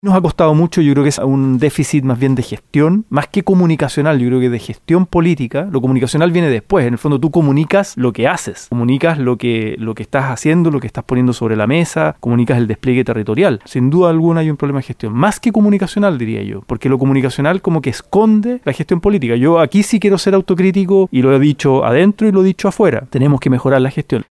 En entrevista con Radio Bío Bío, el también presidente de Revolución Democrática reconoció que durante la campaña de 2021, no dimensionaron la magnitud de la crisis de seguridad que se venía.